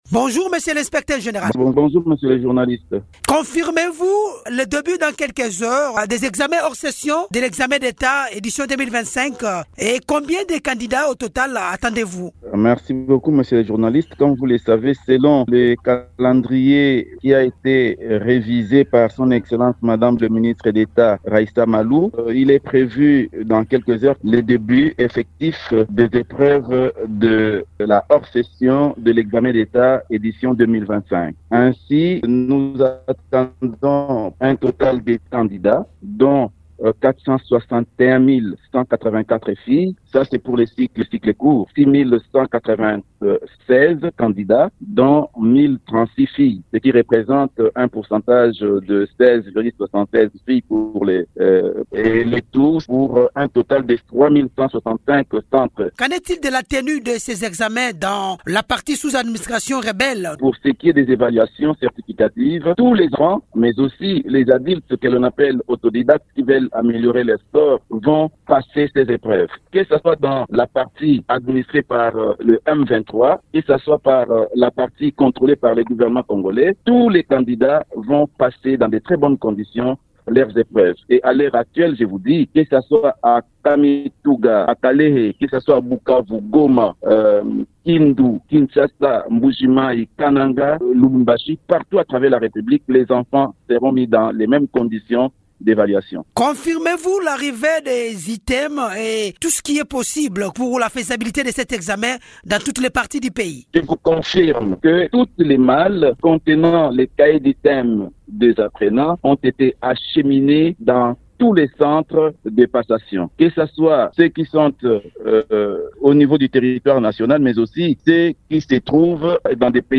Dans une interview accordée à Radio Okapi, il précise que ces candidats seront répartis dans plus de 3 000 centres, y compris dans certains pays frontaliers.